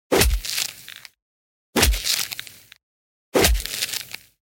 Download Free Street Fighter Sound Effects